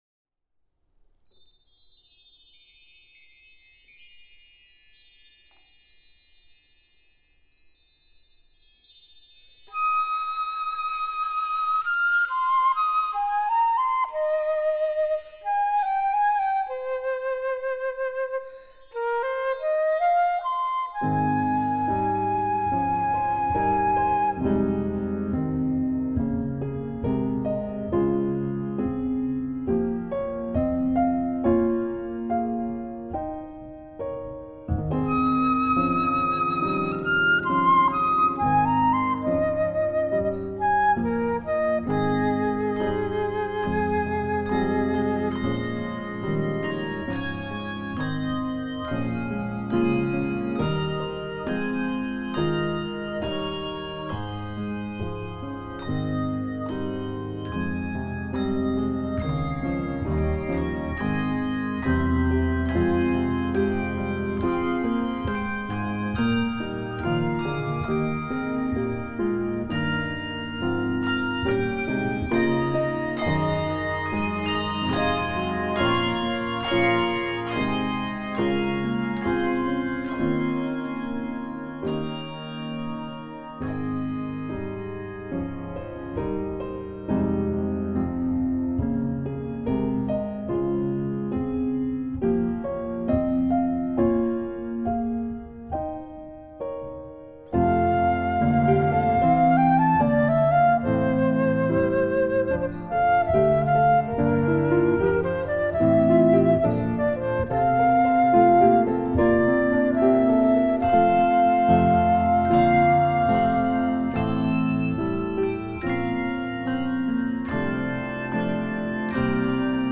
Voicing: Full Score